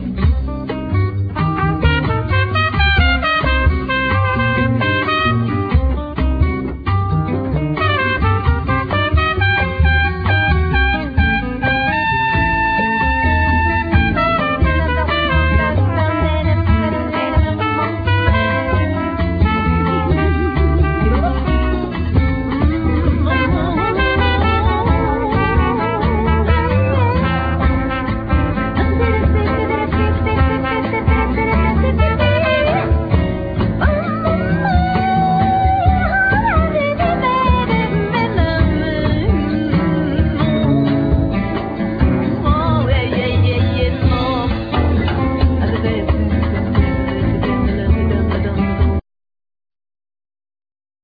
Violin,Vocal,Kazu
Ac.guitar
Trumpet
Doublebass
Drums,Percussions